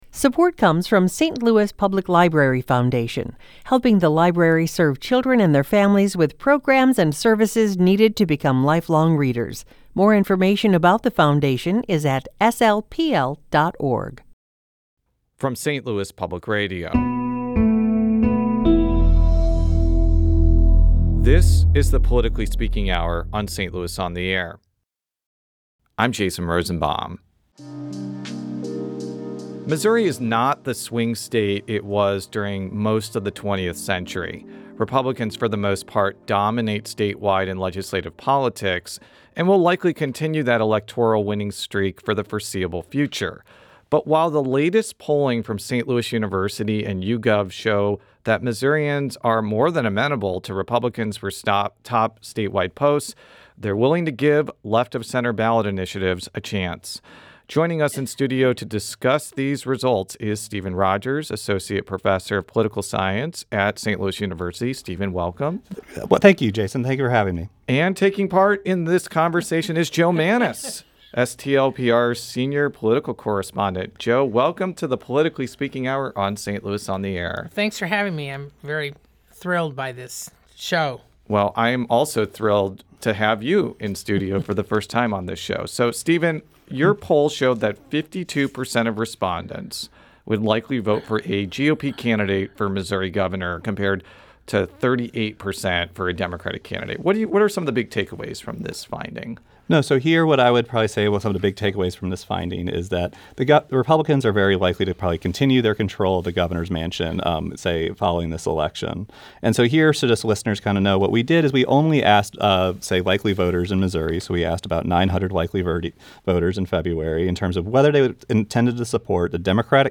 During Friday’s episode of The Politically Speaking Hour on St. Louis on Air, St. Louis University professor